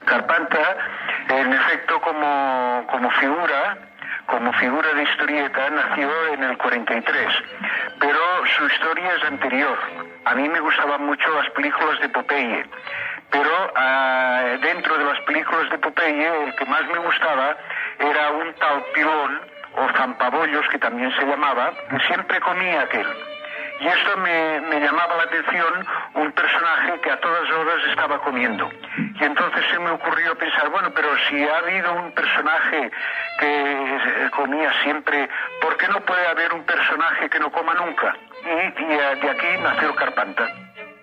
El dibuixant de còmics José Escobar parla del seu personatge "Carpanta" creat l'any 1943
Divulgació
Programa presentat per Joan Manuel Serrat